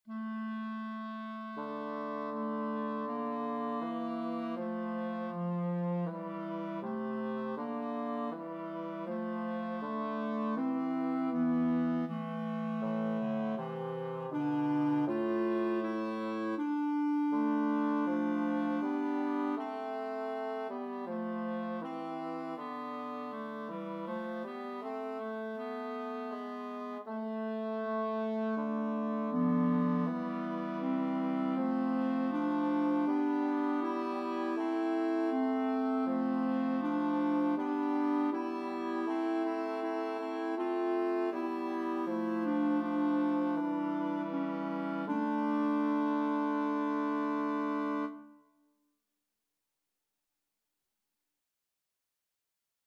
Number of voices: 3vv Voicing: SSA Genre: Sacred, Motet
Language: Latin Instruments: A cappella